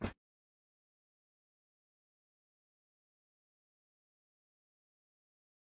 描述：男子坐在床上